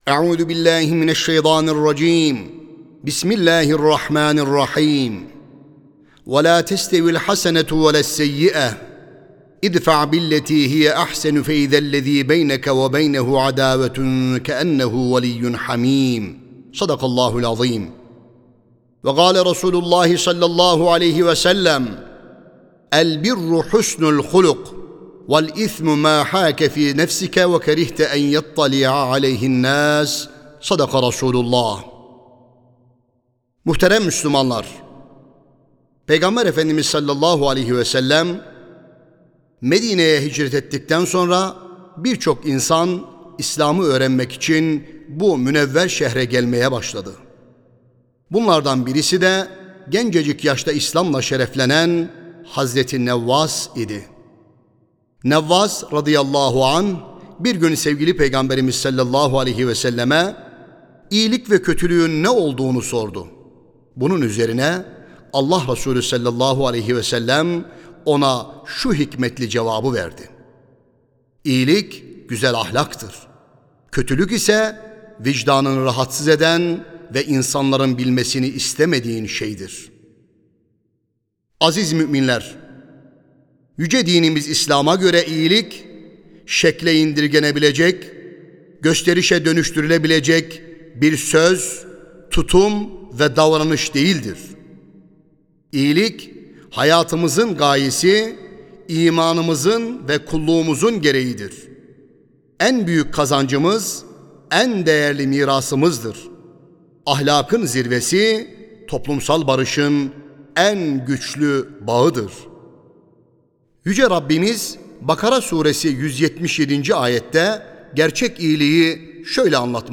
Sesli Hutbe (İyilik Ayı Ramazan).mp3